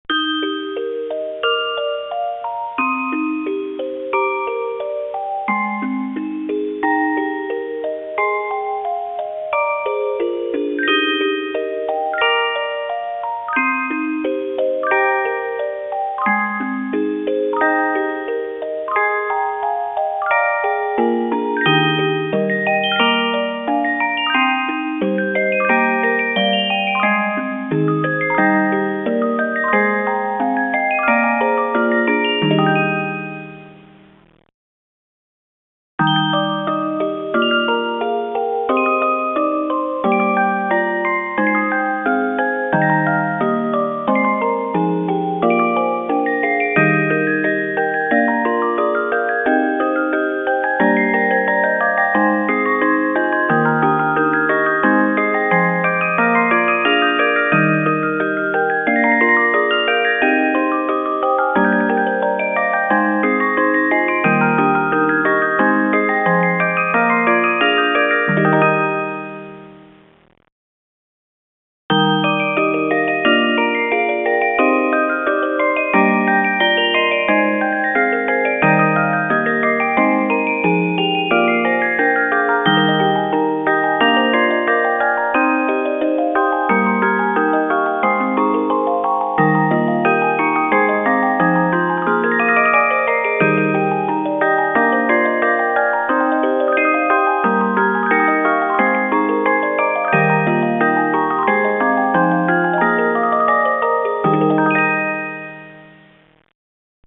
オルゴール「カノン」